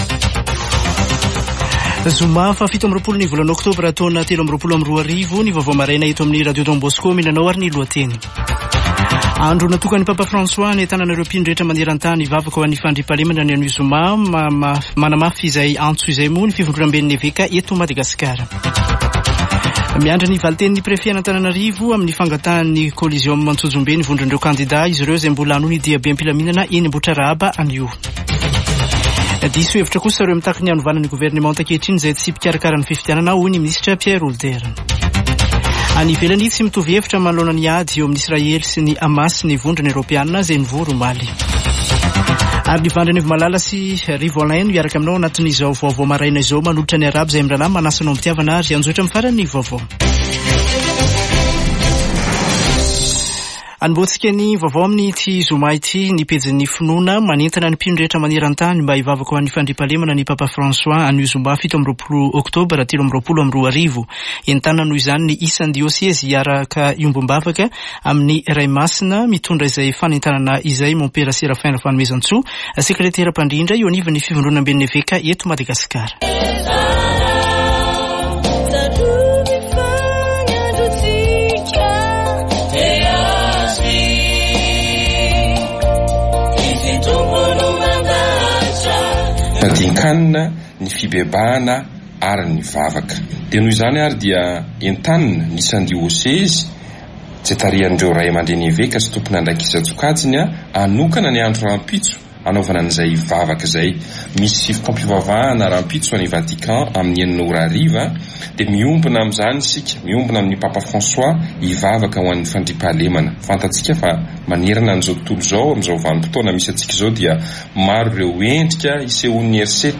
[Vaovao maraina] Zoma 27 ôktôbra 2023